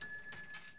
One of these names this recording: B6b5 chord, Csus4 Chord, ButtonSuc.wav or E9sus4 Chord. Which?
ButtonSuc.wav